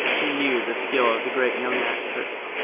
Tag: 男性 语音 语音 糖果